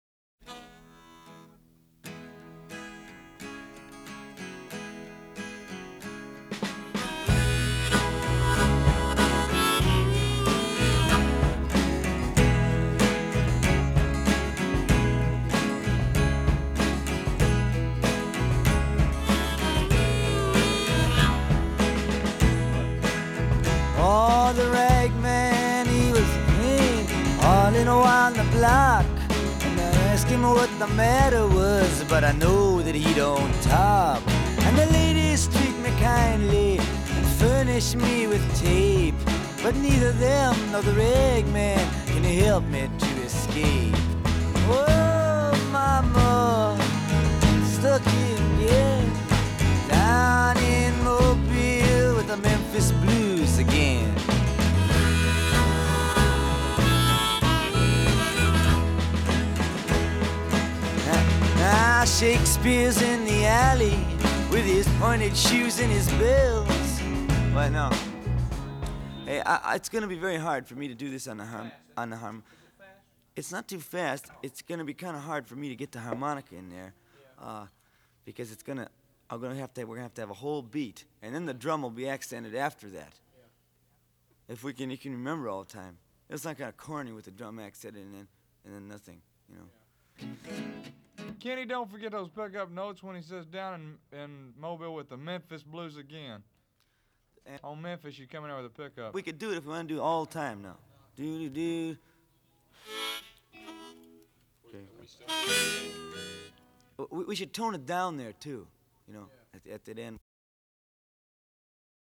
studio outtakes